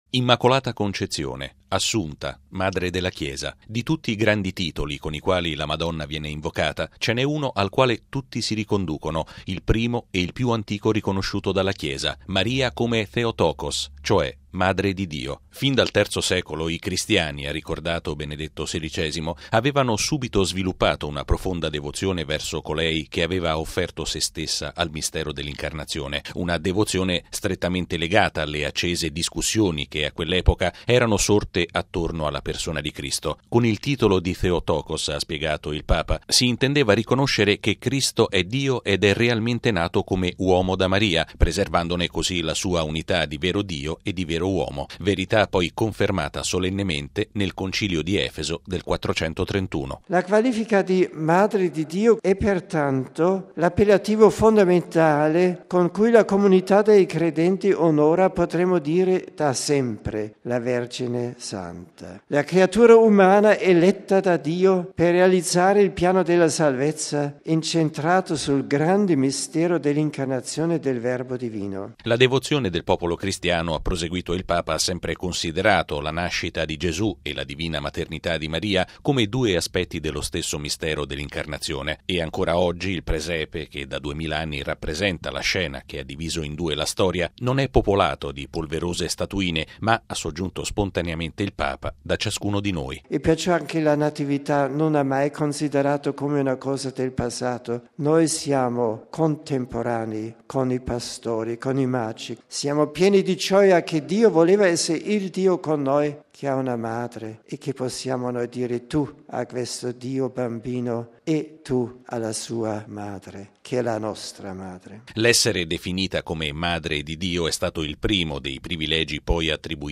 ◊   Una catechesi su Maria “Madre di Dio” - il titolo con il quale la Chiesa invoca la Vergine il primo dell’anno - è stata svolta questa mattina da Benedetto XVI nella prima udienza generale del 2008. Il Papa ha parlato in Aula Paolo VI davanti a circa settemila persone, sviluppando una riflessione mariana tra storia e spiritualità, affidando alla Madonna l’anno appena iniziato perché, ha detto, ci aiuti “a camminare più speditamente sulle vie del bene”.